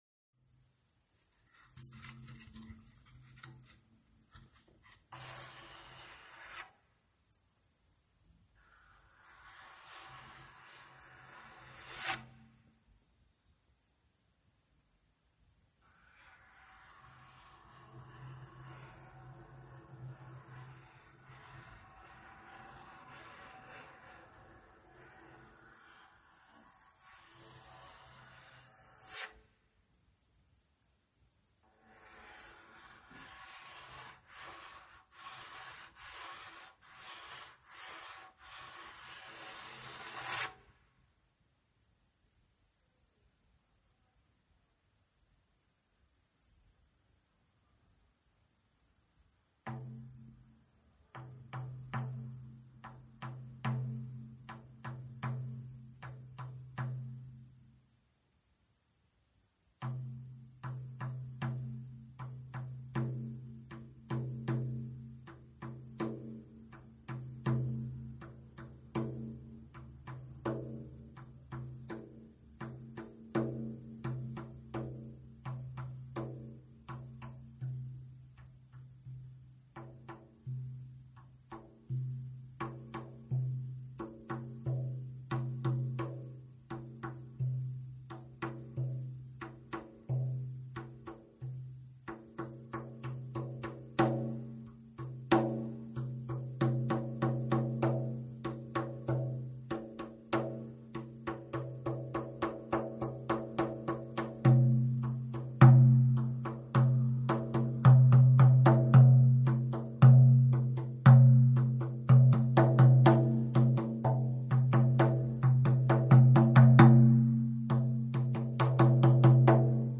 Siit lehelt saad kuulata ja alla laadida järgmist faili: MAYADE TERVENDUS TRUMMIRÄNNAK - juhendatud meditatsioon Ammutame iidset väge! Seanss salvestatud aastal 2020
* kogeda šamaanirännakut trummi saatel mõnusasti oma kodus
Aga kui võtsin trummi, hakkas tulema meloodia ja lahti rulluma seesama maagiline pilt, mida mõned ööd tagasi mediteerides nägin.